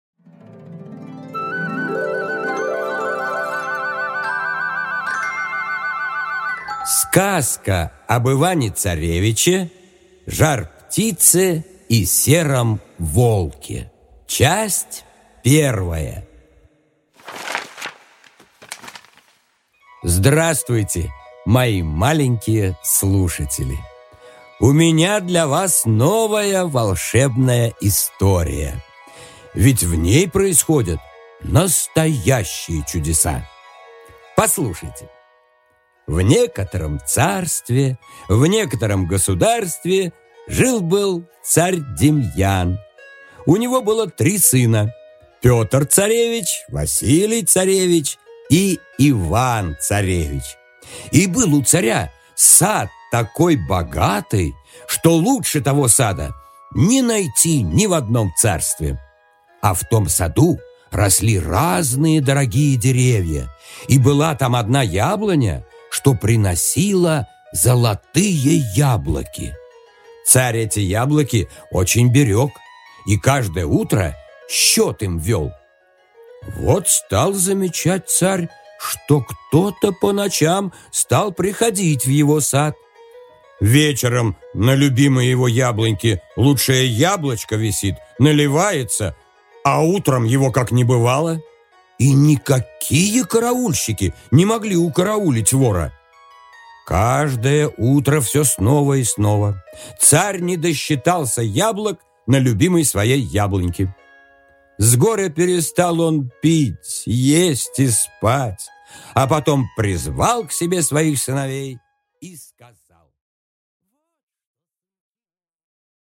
Аудиокнига Сказка об Иване-царевиче, Жар-птице и о сером волке | Библиотека аудиокниг